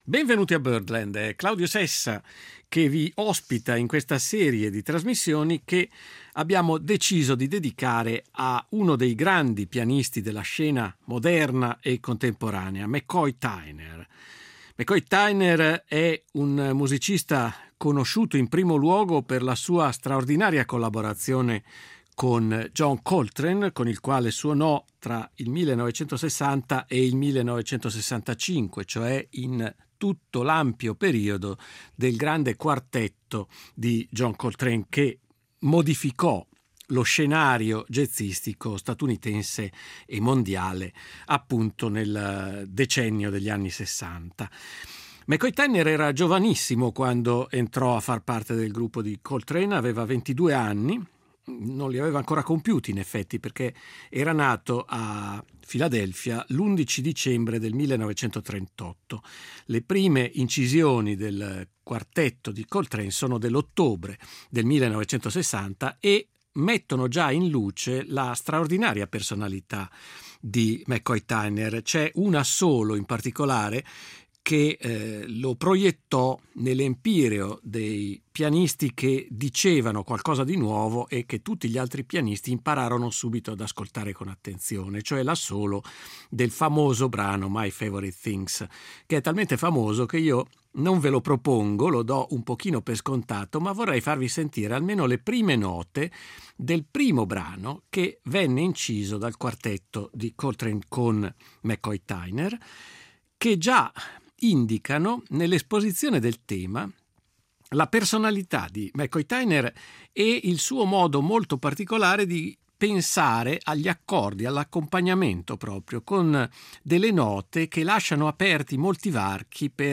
Nel suo stile, alternativo a quello di Bill Evans o di Herbie Hancock, spicca il potente uso della mano sinistra, l’utilizzo di scale modali e di accordi per quarte, un’energia sonora spinta spesso al parossismo.